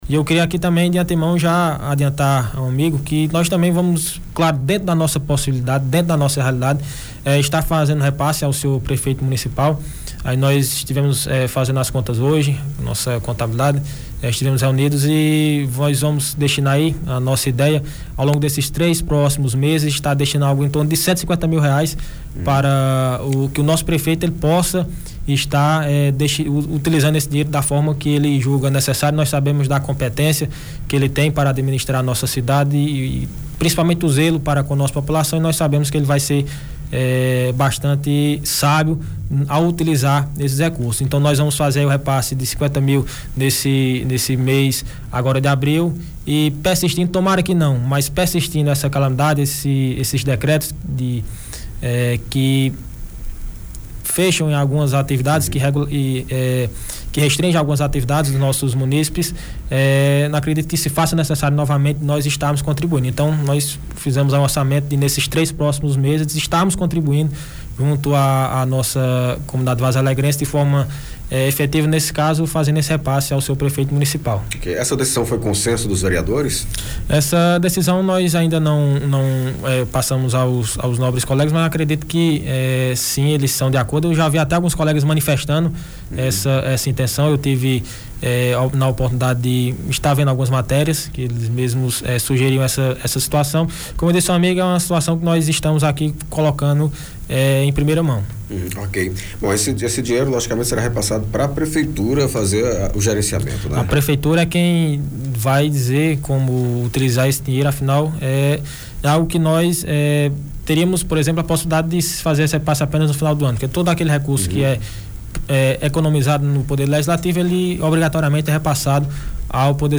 A novidade foi dada pelo vereador presidente da Câmara, José Dener em entrevista à FM Cultura.
JOSE-DNER-ENTREVISTA.mp3